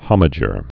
(hŏmə-jər, ŏm-)